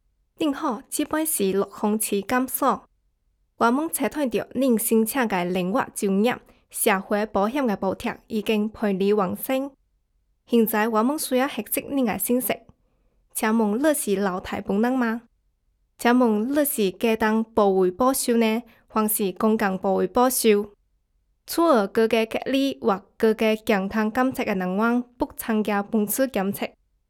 数据堂TTS录音棚通过清华大学建筑环境检测中心检测，达到专业级NR15声学标准，混响时间小于0.1秒，背景噪音小于20dB(A)。
中文潮汕话，标准女声